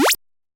8位视频游戏的声音 " zapbonus
描述：一声短促的吱吱声 使用SFXR创建。
Tag: 低保 8位 复古 视频 游戏 副井 吱吱 芯片 街机 吱吱响 抽取 视频游戏